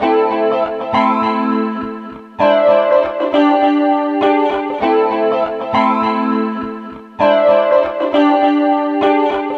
Sons et loops gratuits de guitares rythmiques 100bpm
Guitare rythmique 24